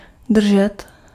Ääntäminen
France: IPA: [tə.niʁ]